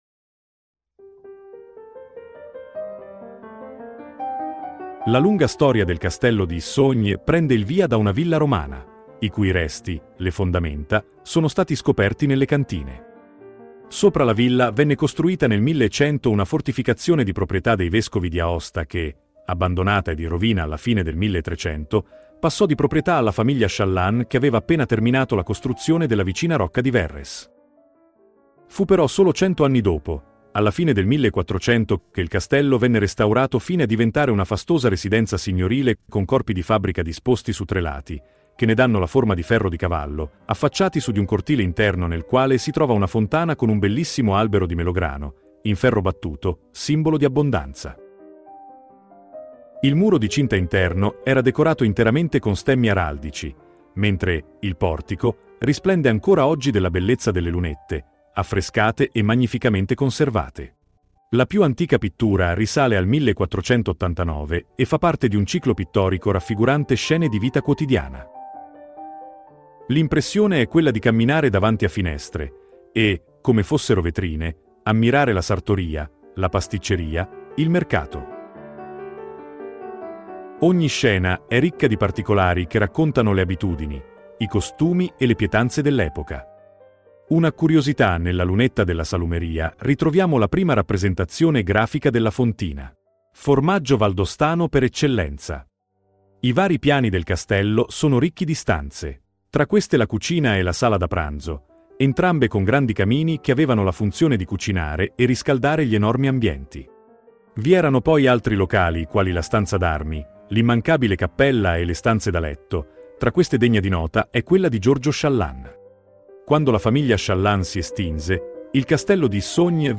Audioguida Castello di Issogne